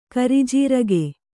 ♪ karijīrage